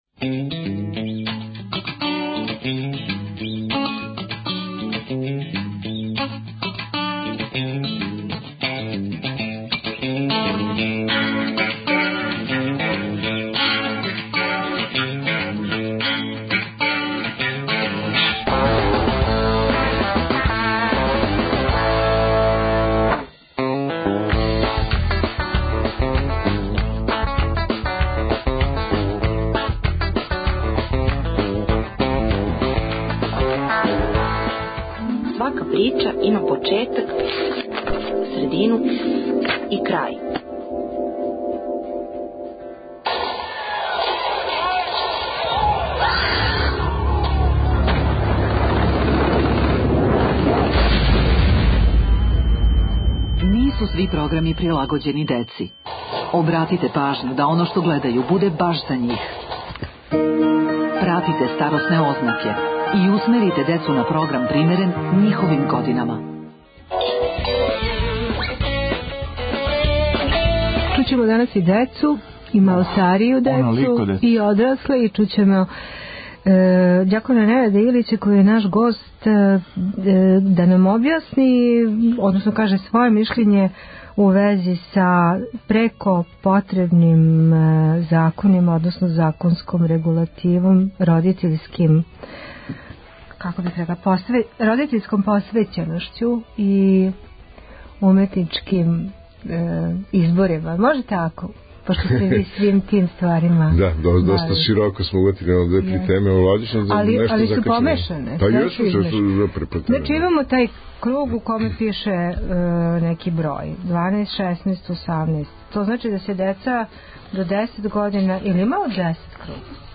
Да ли помаже у васпитавању деце (родитеља), да ли скреће пажњу на насиље и суровост, на експлицитне сцене које дечје око (и глава) не може увек да савлада? О томе говоре најмлађи, студенти